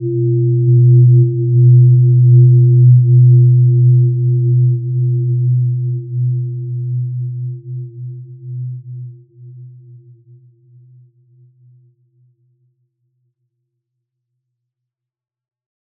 Gentle-Metallic-4-B2-p.wav